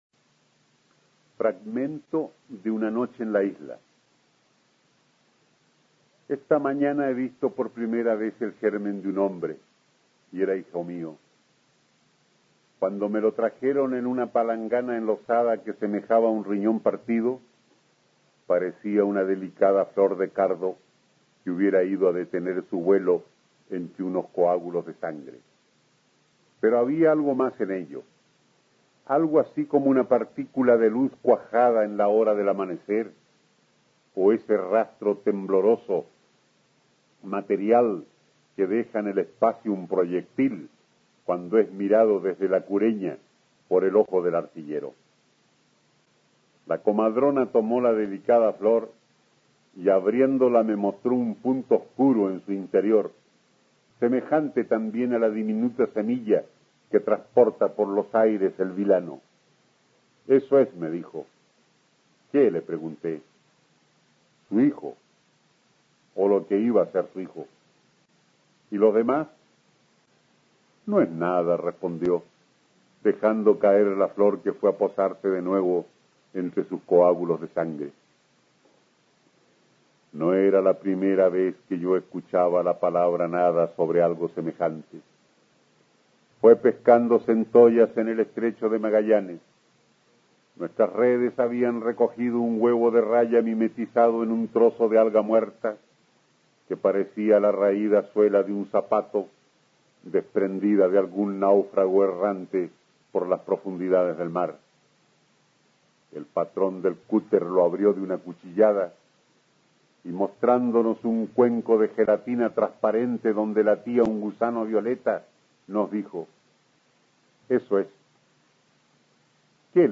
Aquí podrás escuchar al escritor chileno Francisco Coloane (1910-2002), Premio Nacional de Literatura en 1964, leyendo un fragmento de su conmovedor relato "Una noche en la isla".